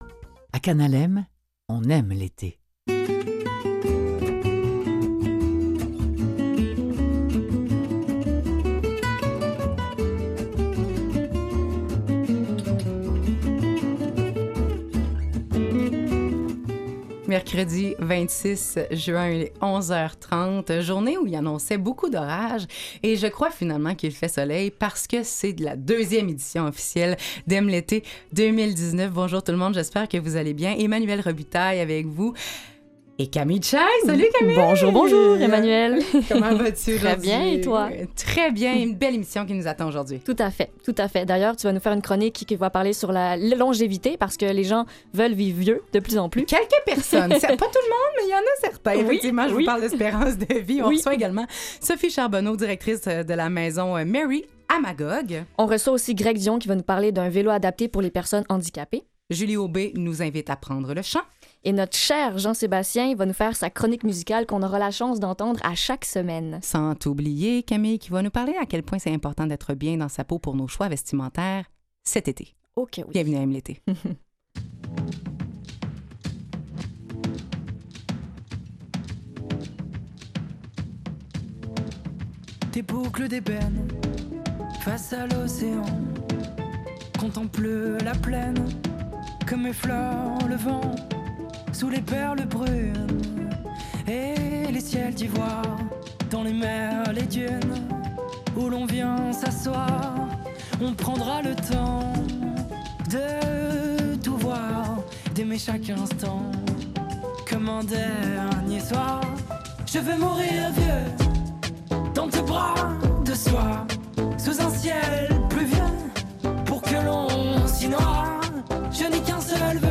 Du lundi au vendredi, à 11h30 c’est M l’été sur nos ondes. Un magazine d’actualité sur le ton léger de l’été avec un tandem de personnes animatrices qui discutent d’enjeux et de défis à relever pour une société plus inclusive.